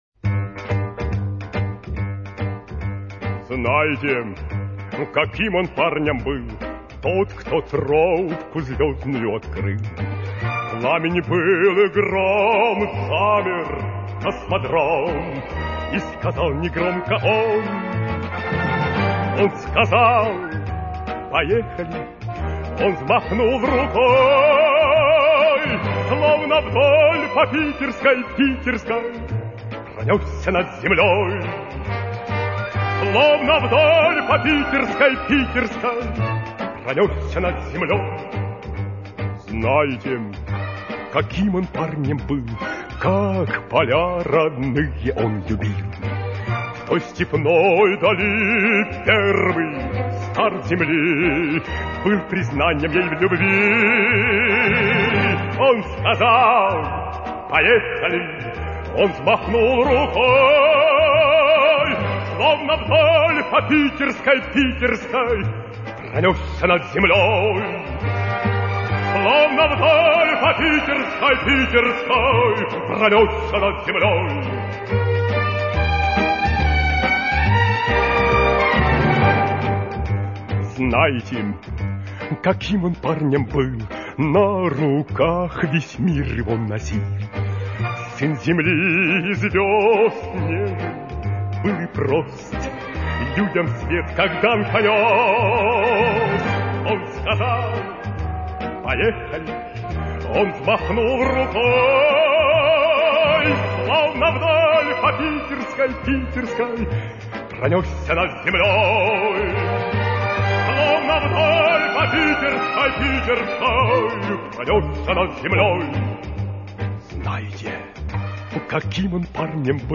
Юрий Алексеевич Гагарин: "Поехали!"